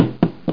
steps.mp3